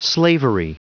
Prononciation du mot slavery en anglais (fichier audio)
Prononciation du mot : slavery